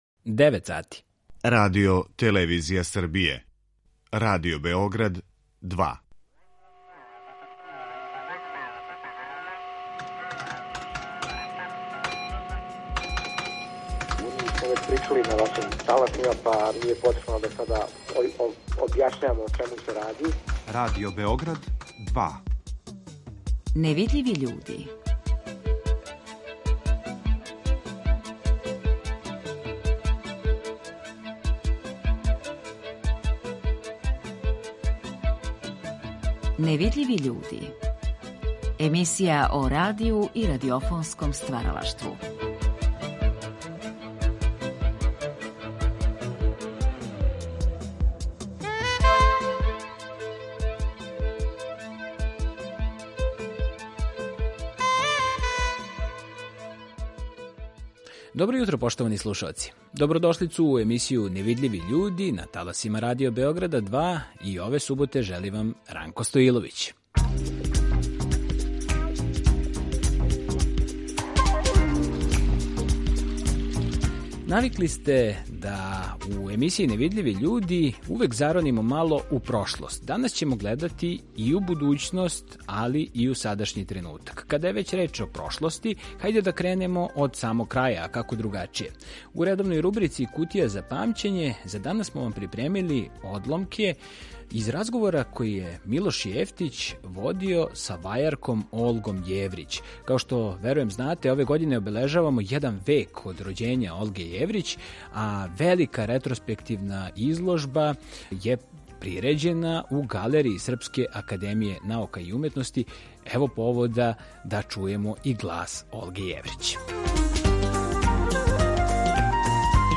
Емисија о радију и радиофонском стваралаштву